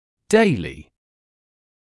[‘deɪlɪ][‘дэйли]ежедневный, повседневный